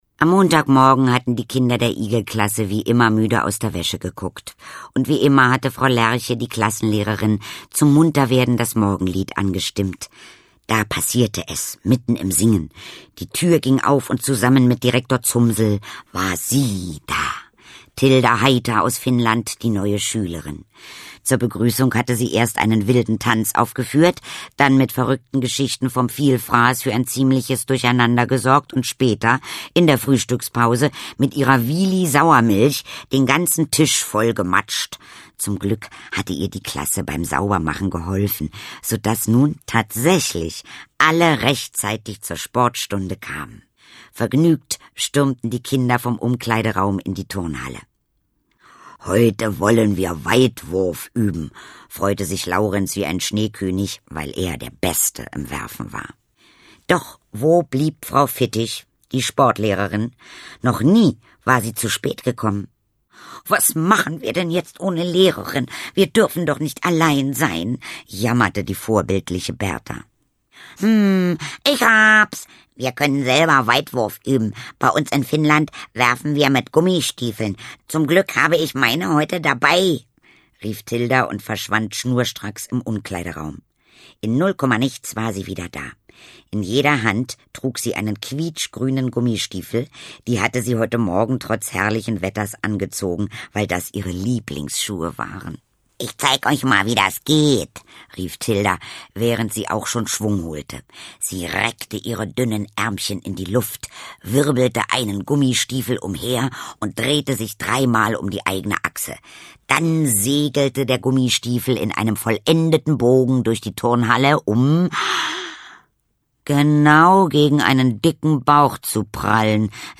Mitarbeit Sprecher: Andreas Fröhlich, Katharina Thalbach, Gudrun Mebs